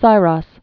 (sīrŏs) also Sí·ros (sērôs)